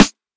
DOITAGAINSNR.wav